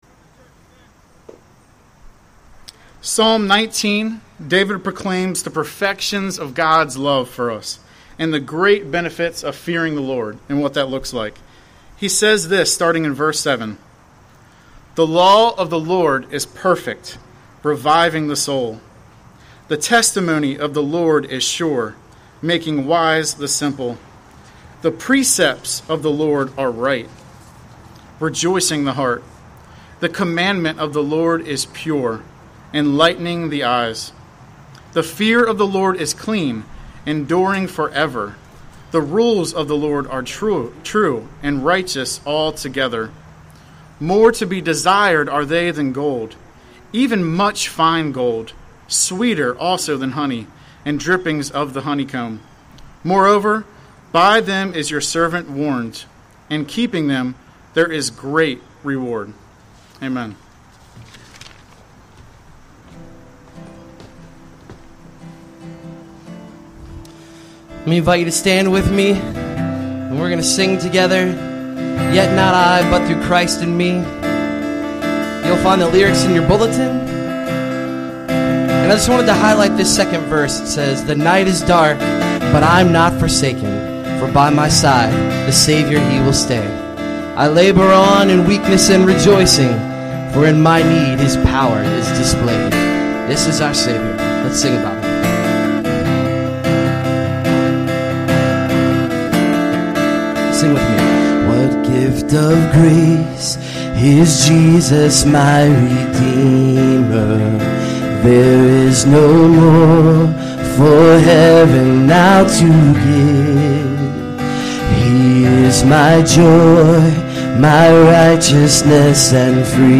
Sermon Series on Book of James